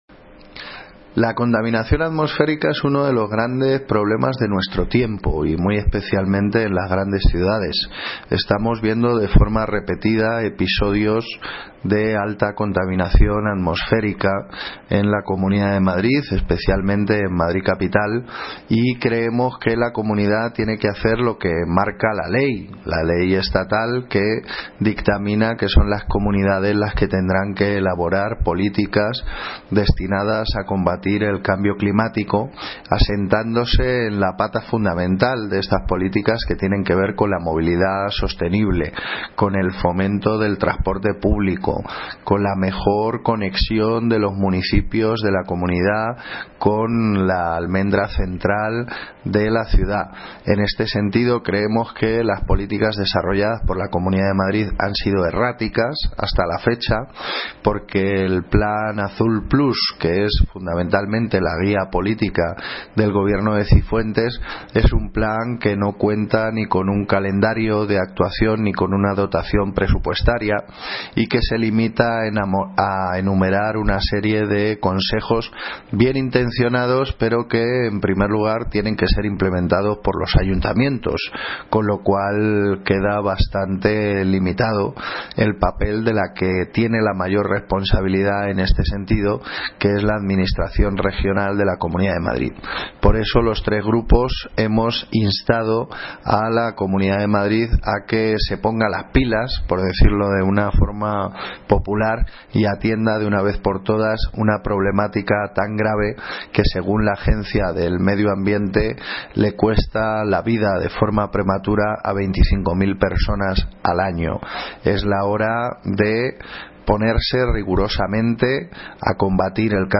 Audio - Gabriel Ortega (Portavoz del Grupo Ganar Móstoles) Sobre Moción Cambio Climático